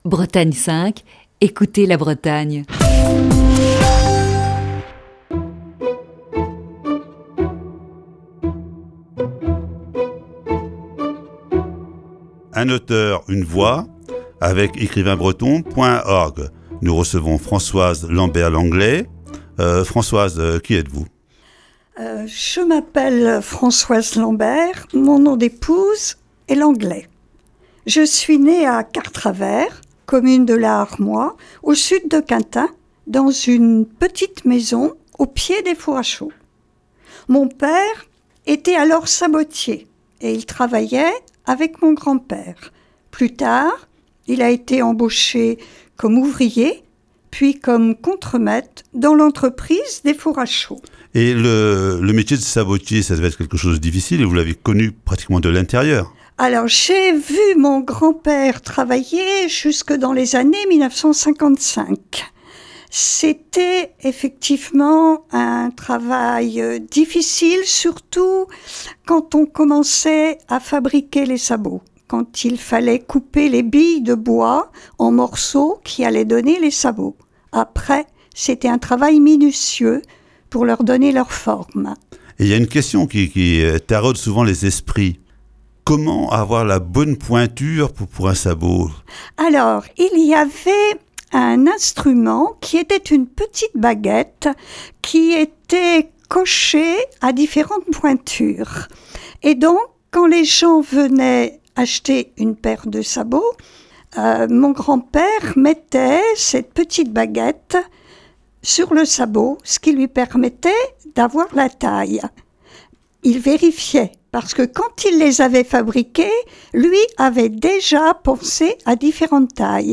(Émission diffusée le 21 octobre 2019).